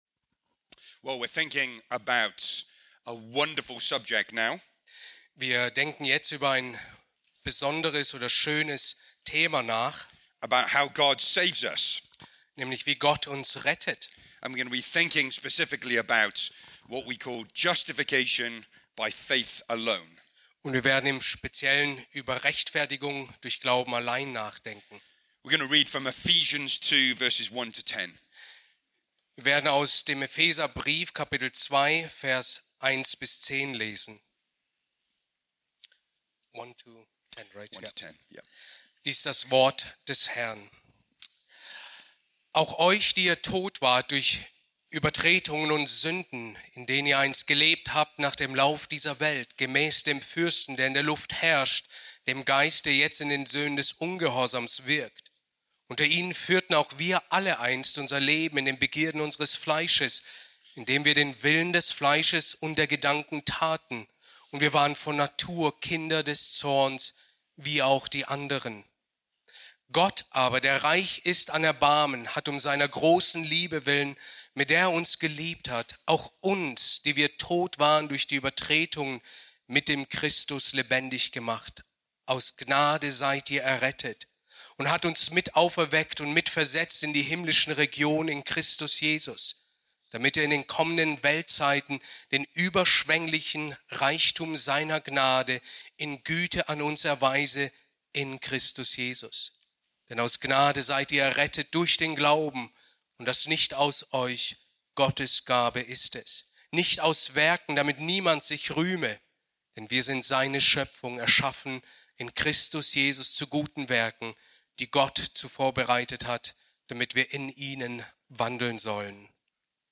Predigt aus der Serie: "ERG-Konferenz 2026"